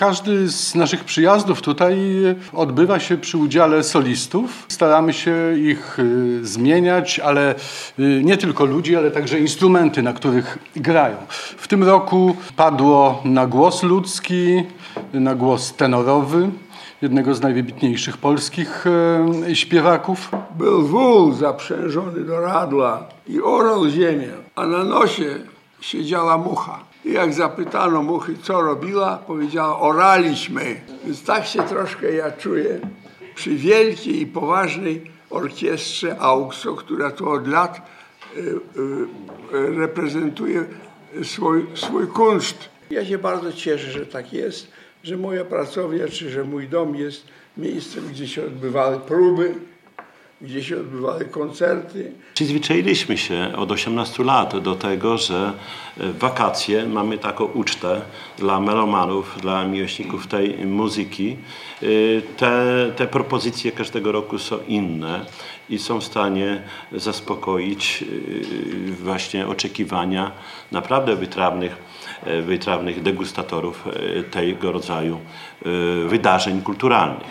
Konferencja prasowa poprzedzająca 18. Letnią Filharmonię AUKSO Wigry 2017 odbyła się w czwartek w Suwalskim Ośrodku Kultury.